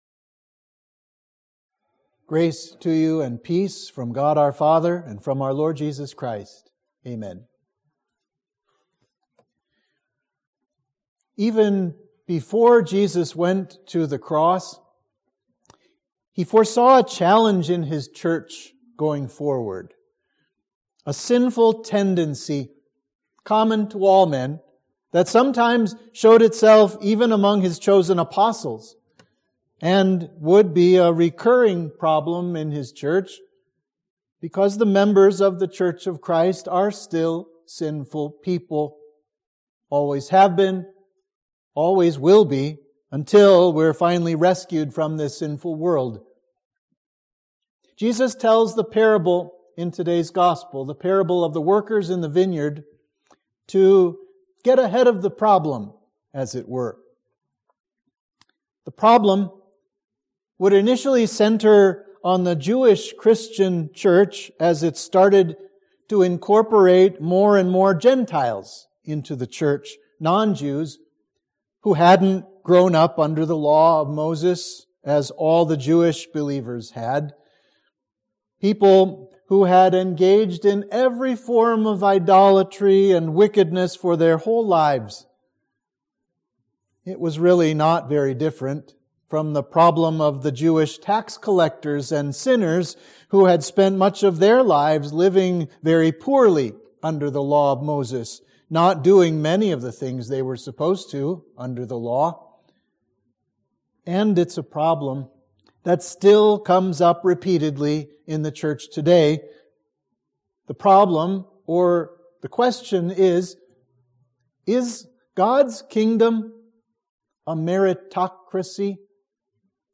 Sermon for Septuagesima